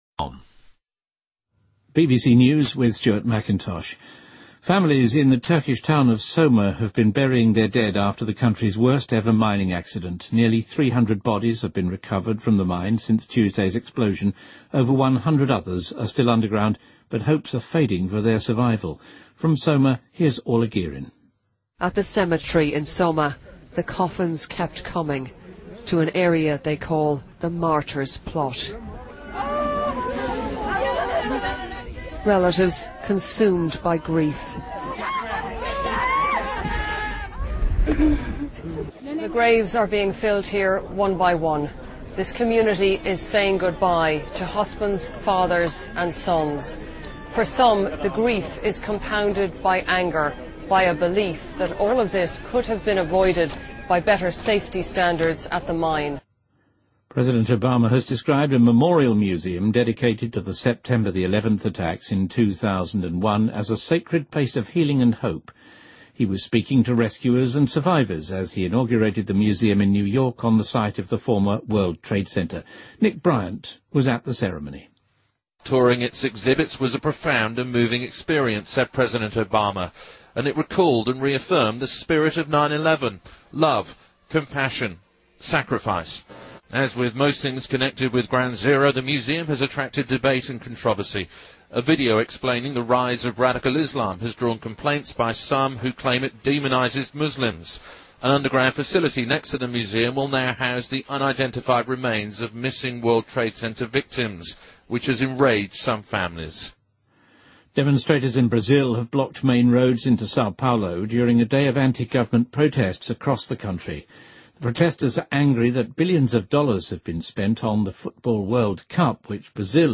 BBC news,波斯尼亚和塞尔维亚遭遇了自120年前有记录以来最严重的暴雨